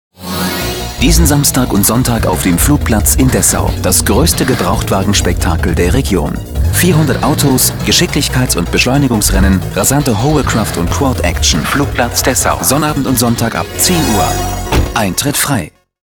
warm, freundlich, angenehm, verbindlich, symphatisch, jugendlich bis beste Jahre, wandelbar, seriös,
Kein Dialekt
Sprechprobe: Werbung (Muttersprache):